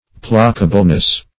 Placableness \Pla"ca*ble*ness\, n. The quality of being placable.